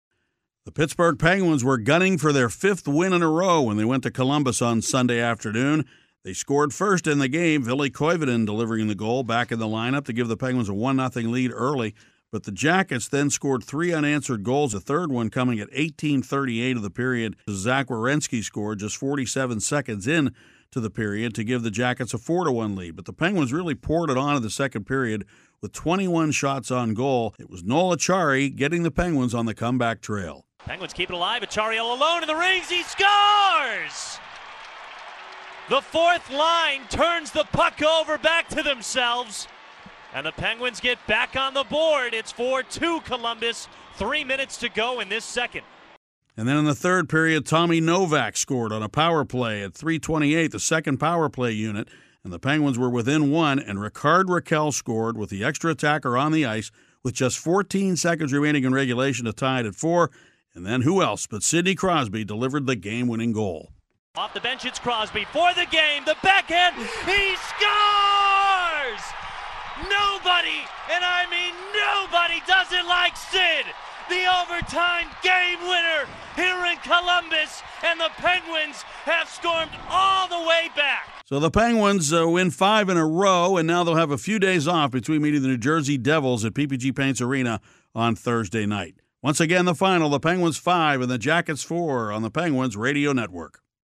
The Penguins have had their share of third-period heartbreak this season, but yesterday they were on the other end of it, with a tremendous comeback and an overtime win in Columbus.  Paul Steigerwald has the recap.